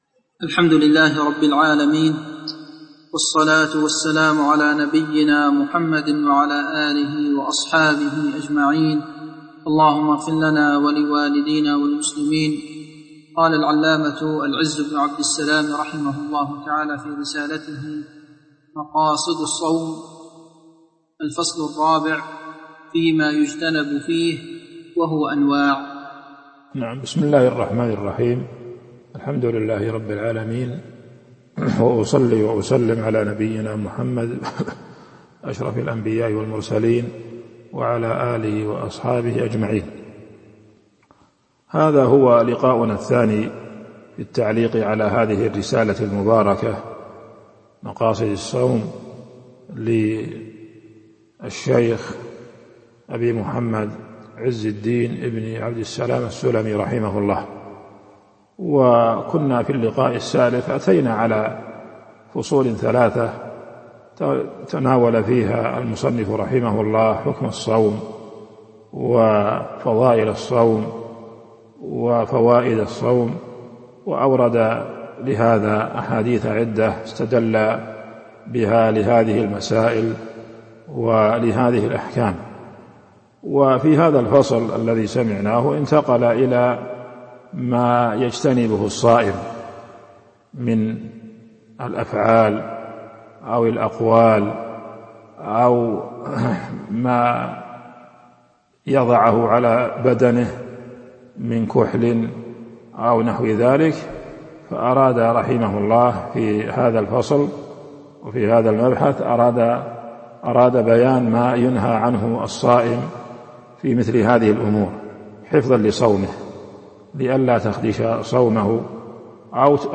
تاريخ النشر ١٣ رمضان ١٤٤٢ هـ المكان: المسجد النبوي الشيخ